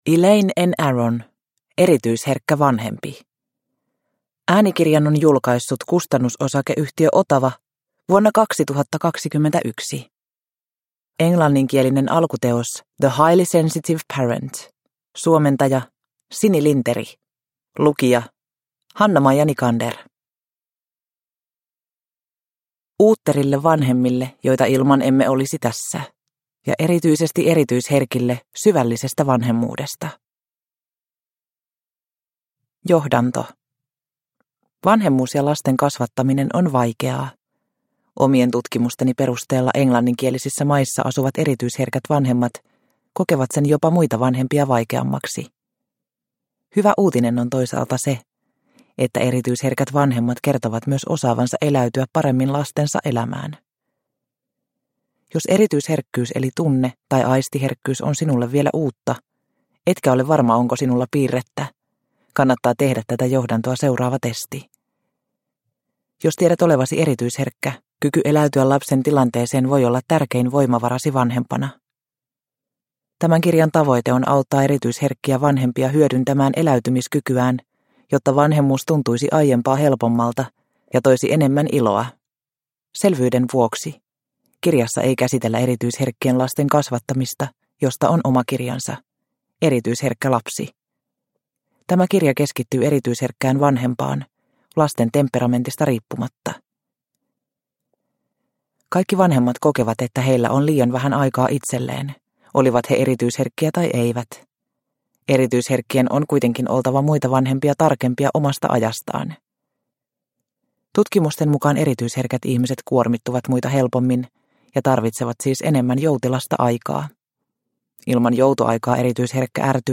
Erityisherkkä vanhempi – Ljudbok – Laddas ner